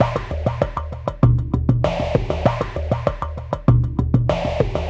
Index of /90_sSampleCDs/Spectrasonic Distorted Reality 2/Partition A/04 90-99 BPM